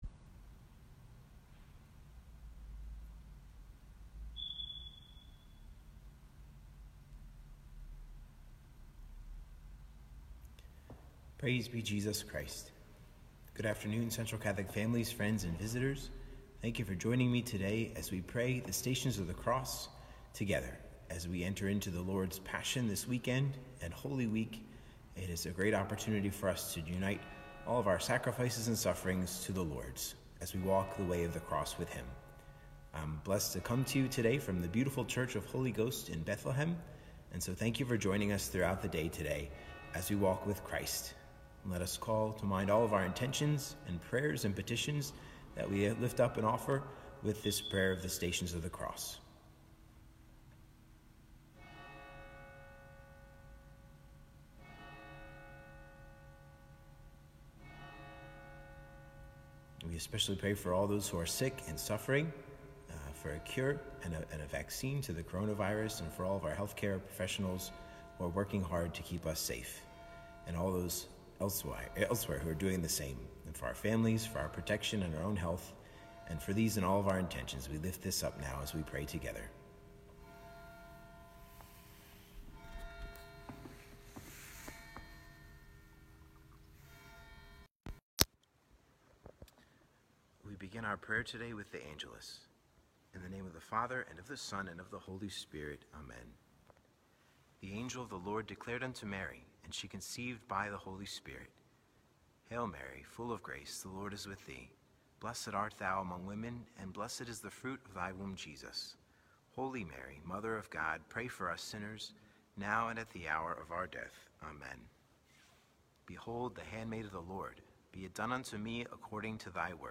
The Stations of the Cross, Holy Ghost Church, Bethlehem, PA
Sermon or written equivalent